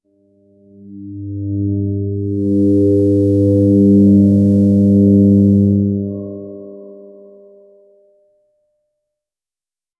a brief sci-fi sequence that begins with a resonant, futuristic hum, building anticipation. This could be followed by a shimmering, ascending tone, and then a crisp, echoing chime. 0:10 Created Dec 28, 2024 4:52 PM
a-brief-sci-fi-sequence-t-yxv5r72b.wav